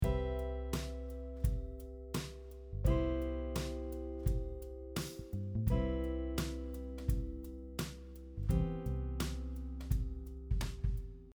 Here is an example of drop 2 chord inversions over one long Major 7 chord.
drop 2 chords over a long Major 7 chord application example